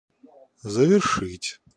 Ääntäminen
IPA : /kən.ˈkluːd/